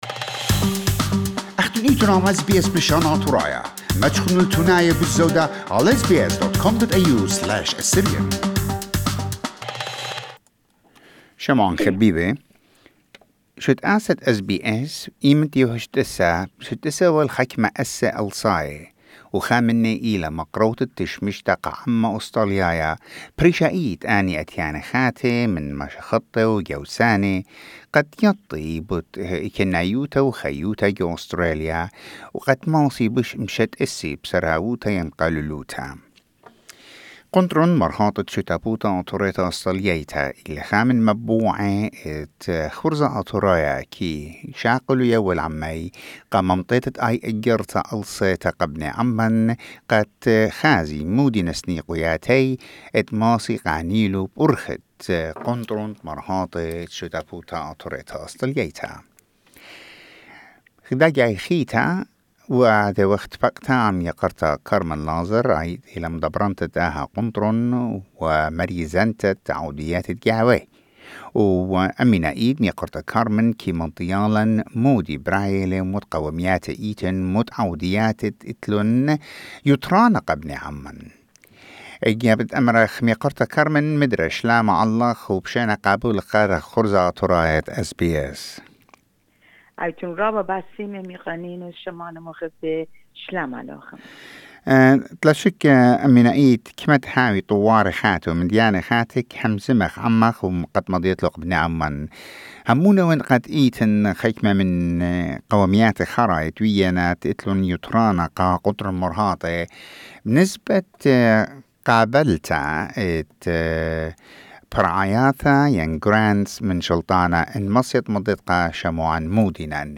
SBS Assyrian View Podcast Series